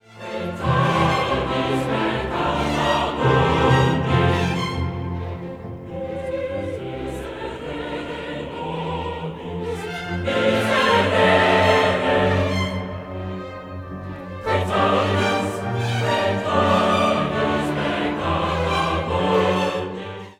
All sections are for chorus except “Domine Deus”, which is for solo quartet.
The movement is conceived as a restless, urgent song whose beautiful melodies do not support the words.  The violin part is positively furious.
With the exception of “Et in terra pax”,  the choral parts are fairly shouted out.